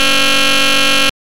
00073_Sound_Buzzer.mp3